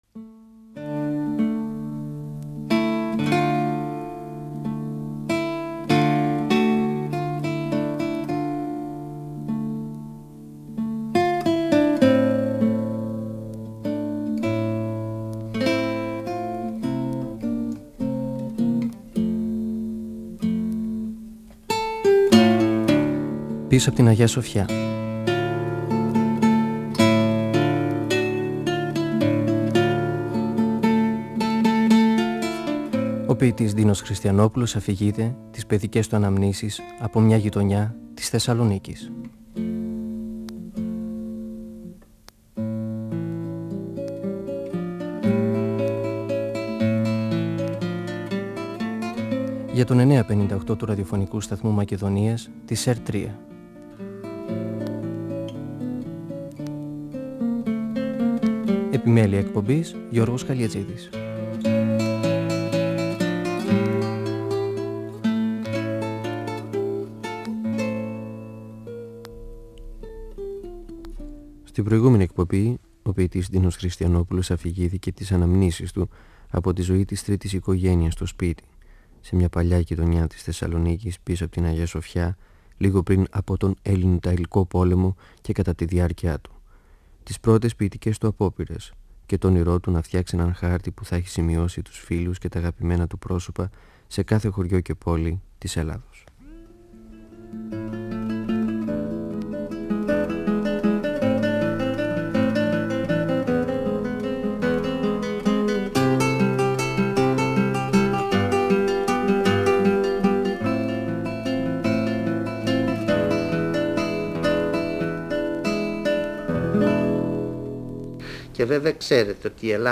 (Εκπομπή 11η) Ο ποιητής Ντίνος Χριστιανόπουλος (1931-2020) μιλά για τις αναμνήσεις του από το μια παλιά γειτονιά της Θεσσαλονίκης, πίσω απ’ την Αγια-Σοφιά, στα τέλη της δεκαετίας του 1930 και στις αρχές της δεκαετίας του 1940. Την επιθυμία του να γράψει σ’ έναν χάρτη της Ελλάδας τα ονόματα όλων των γνωστών και φίλων του.